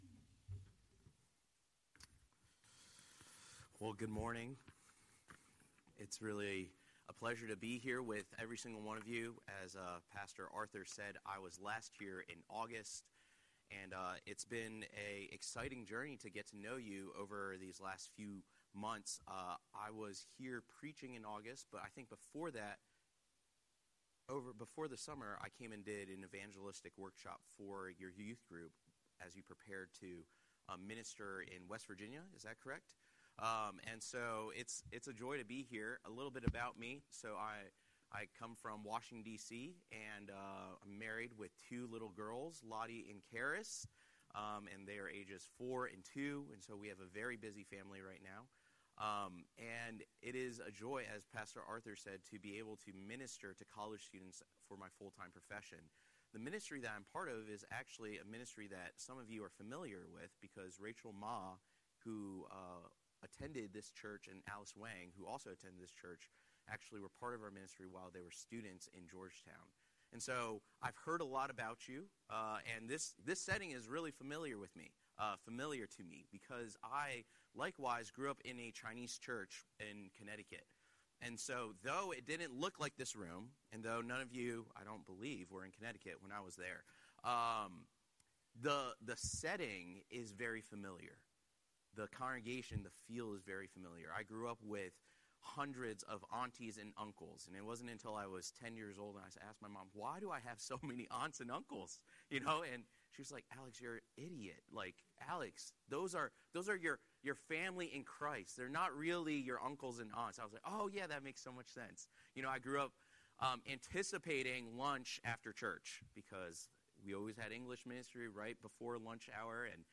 Scripture: 1 Thessalonians 1:2–10 Series: Sunday Sermon